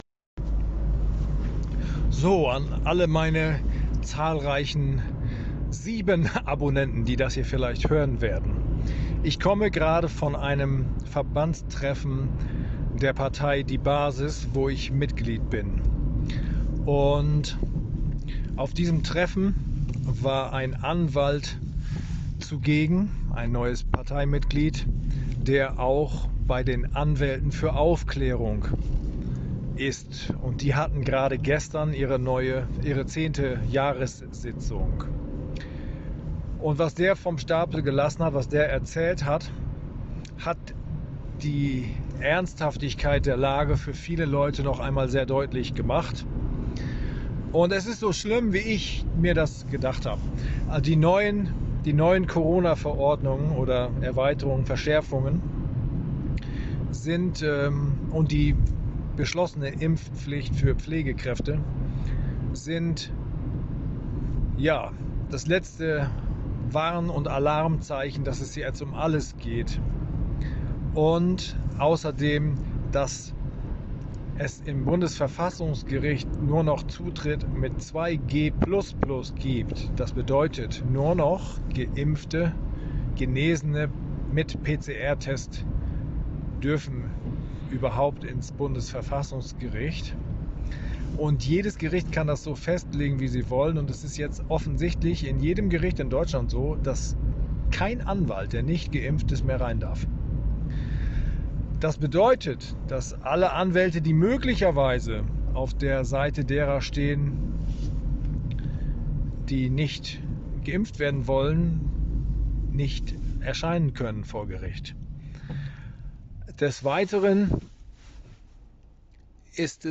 Ein Anwalt der "Anwälte für Aufklärung" erklärt die Ernsthaftigkeit der Lage. Den die Erweiterungen des Infektionsschutzgesetzes sieht vor, dass alle Beteiligten eines Gerichtsverfahrens 2G++ sein müssen.